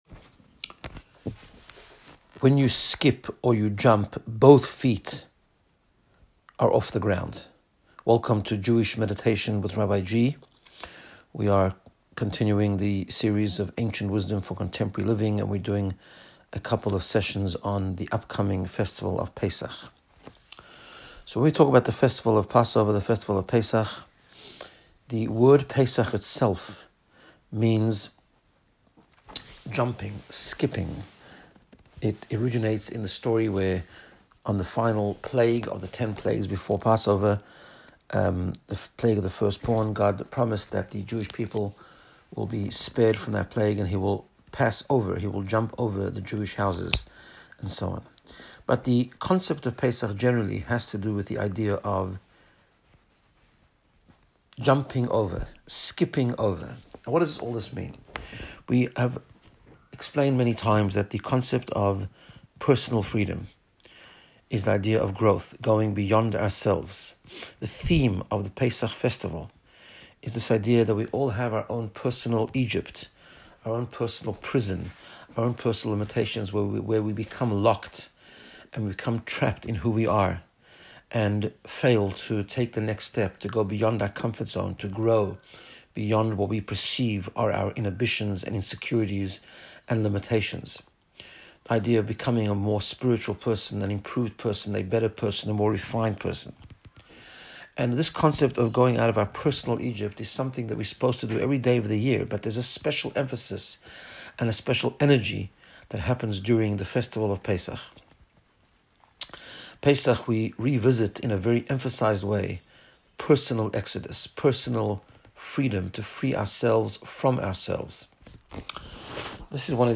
Jewish Meditation